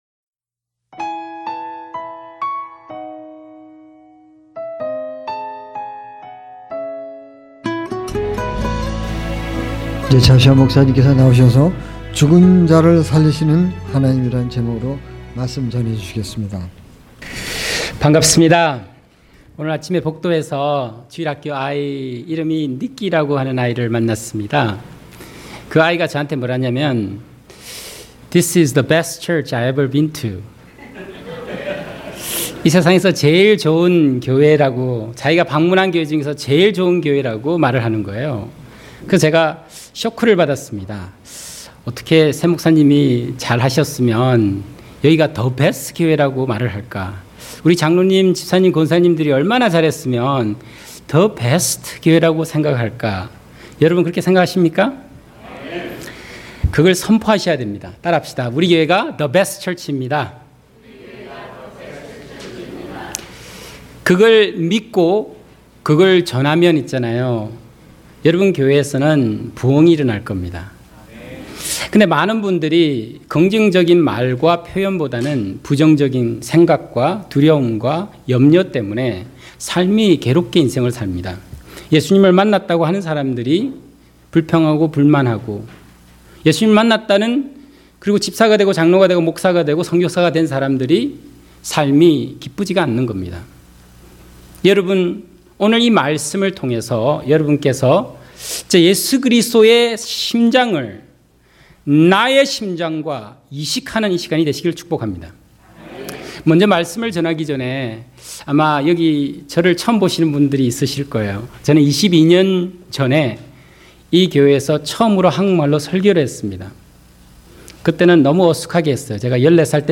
설교: 죽은 자를 살리시는 하나님 요한 복음 11장 38-44절